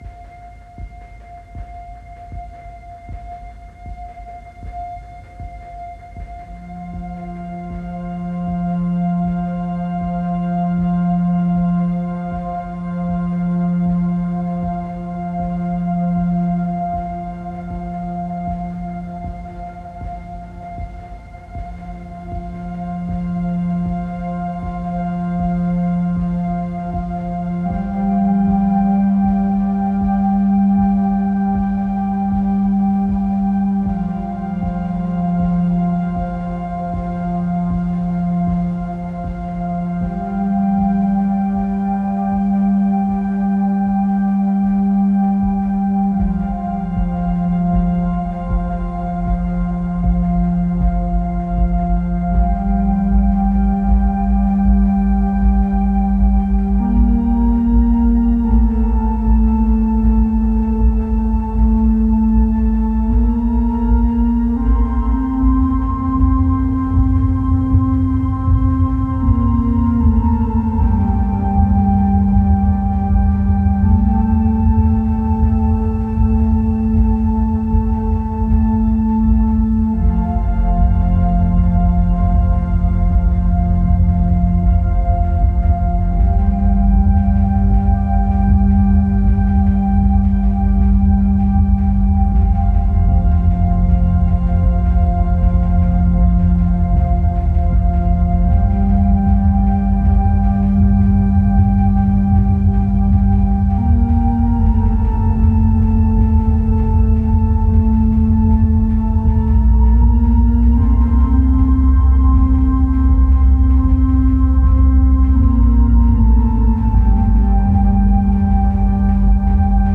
Pulsating synth arpeggios and hazy textures.